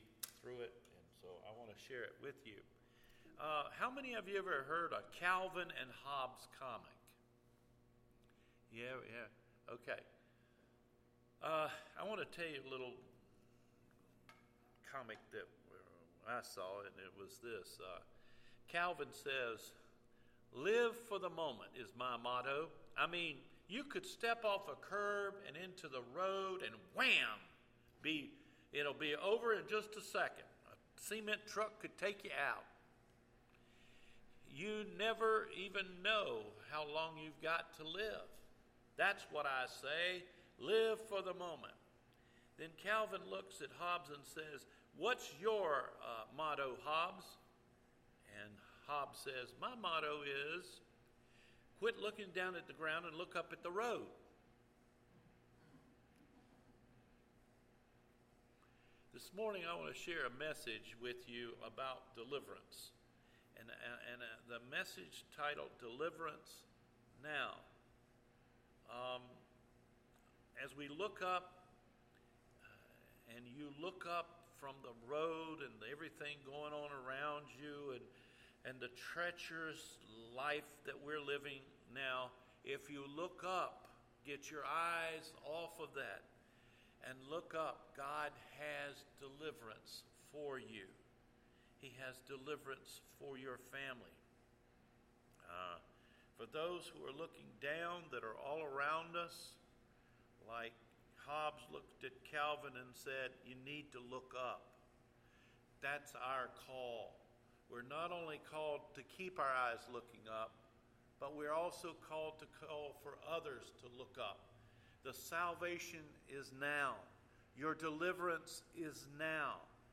DELIVERANCE NOW – AUGUST 30 SERMON